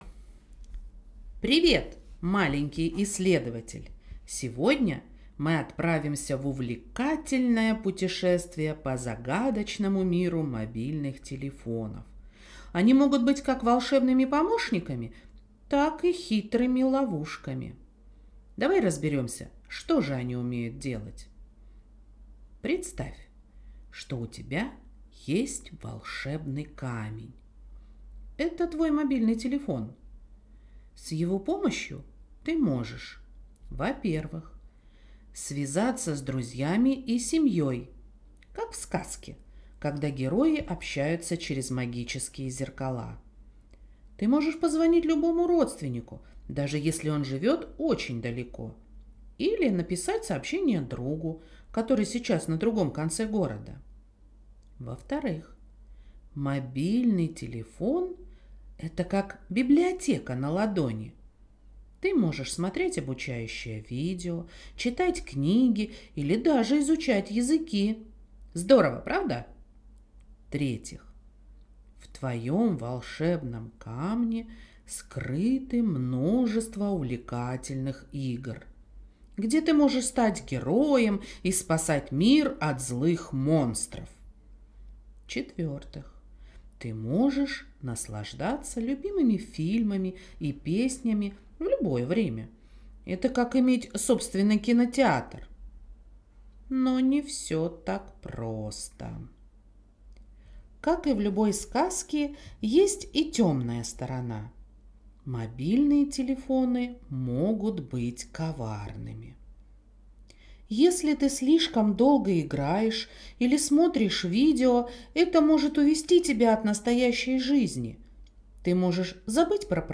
Сказки и рассказы читает